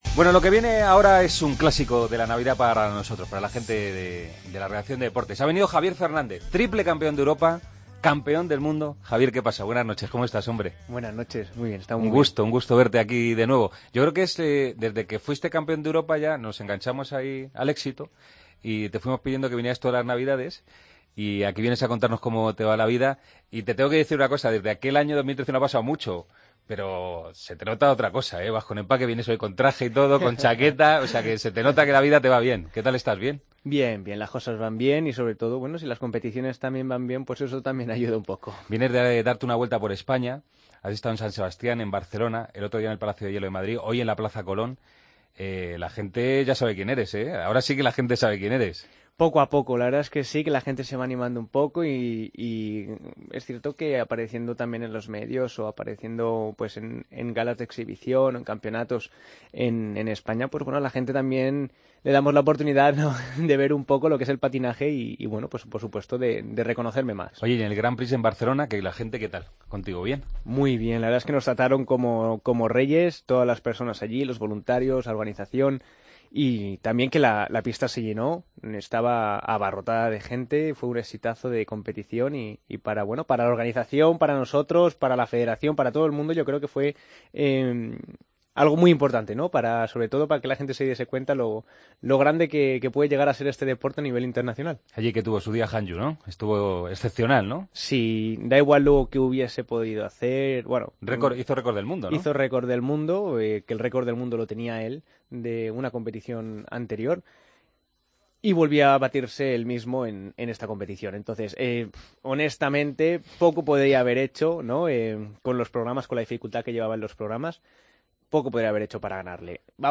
AUDIO: El mejor patinador español del momento visita El Partido de las 12 para contarnos sus planes de cara a los próximos Europeo y Mundial:...